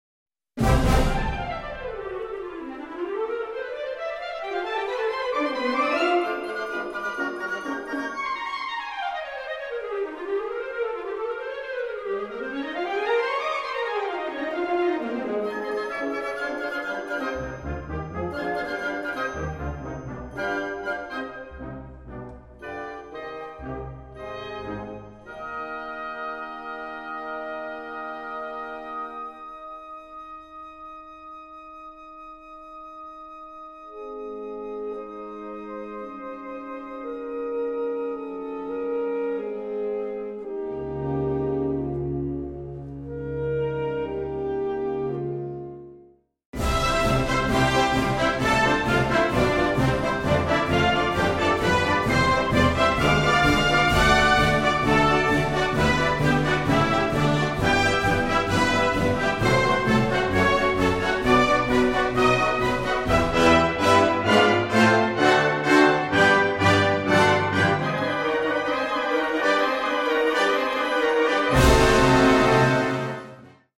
C Major（原調）
トリッキーなリズムと意外性のある転調が印象的な序曲です。